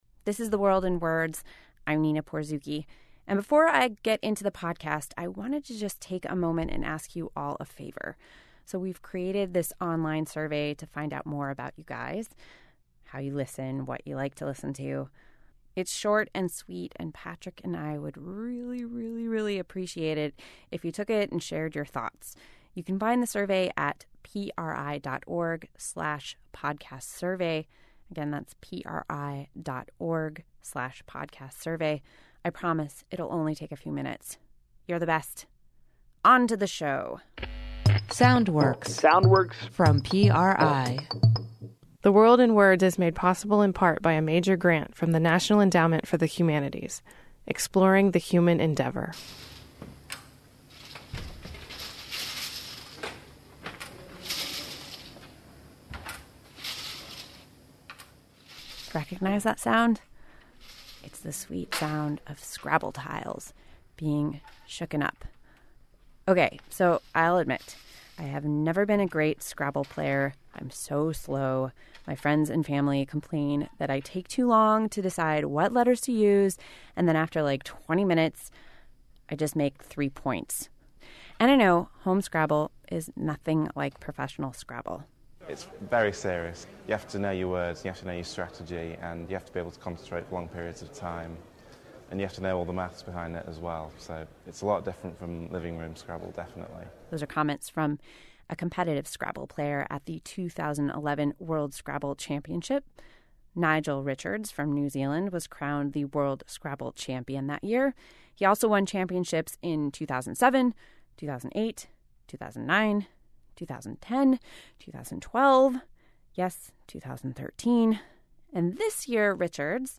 New Zealander Nigel Richards recently rocked the competitive scrabbling playing world when he became the 2015 French Scrabble World Champion. The World in Words digs into the backstory of the Scrabble genius. Also in the podcast we hear from a researcher who has been observing a slow change in the Scottish dialect – Scots seem to be swallowing their R’s.